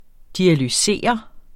Udtale [ dialyˈseˀʌ ]